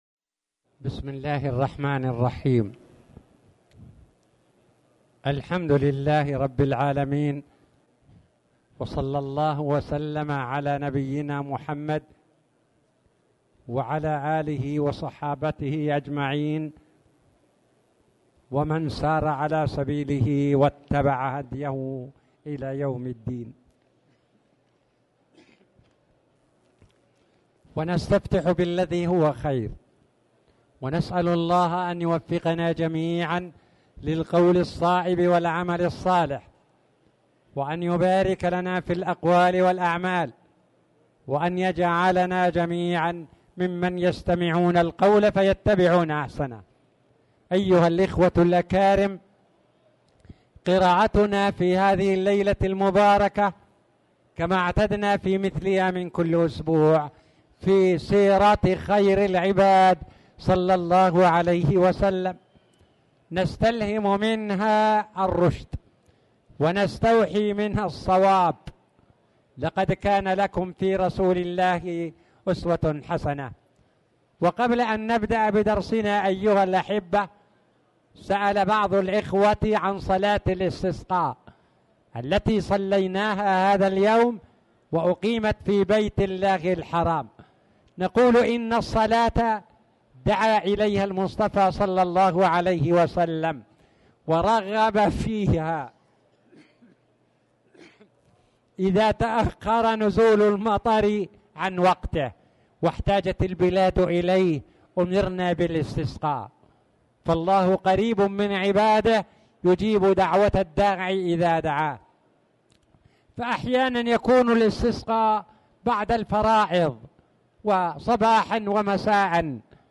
تاريخ النشر ١٠ صفر ١٤٣٨ هـ المكان: المسجد الحرام الشيخ